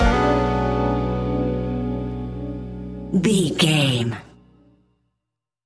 Ionian/Major
B♭
hip hop